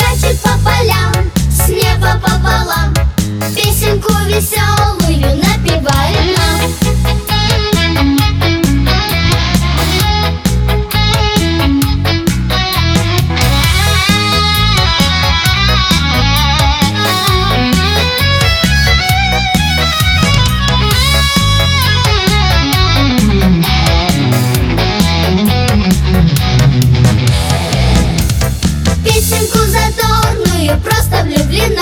А он все равно барабаны туда запихал, бас, свсе дела, потом UVR все это дело разделал, ну такая срань получилась если честно.